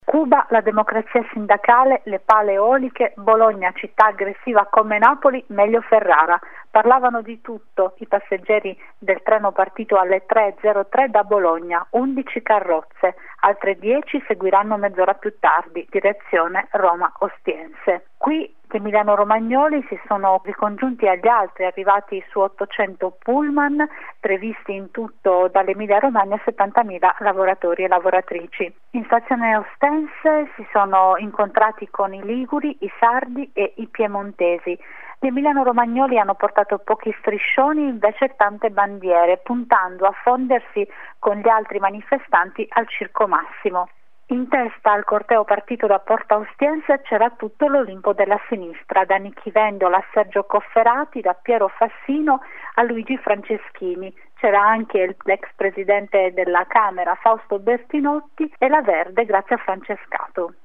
Una diretta non stop che, dalle 06.00 alle 15.30, ha seguito i cinque cortei e il loro arrivo al Circo Massimo.